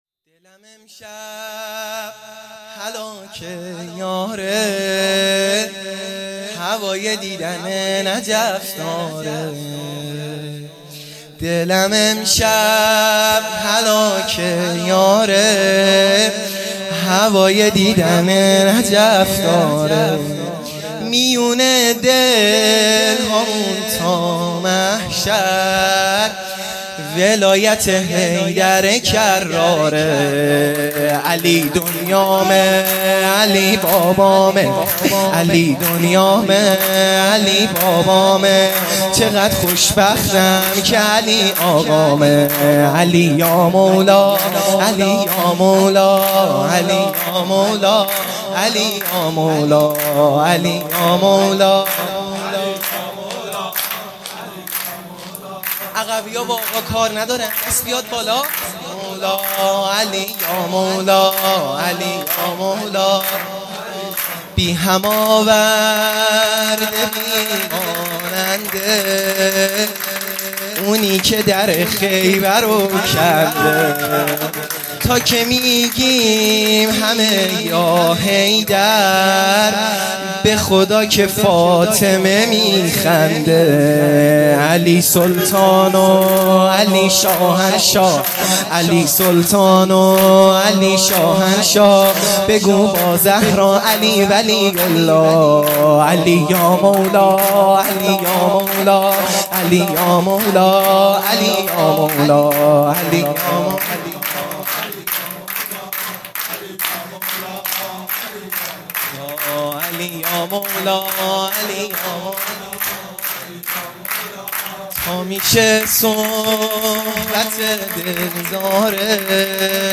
سرود دلم امشب هلاکه یاره
میلاد امام علی علیه السلام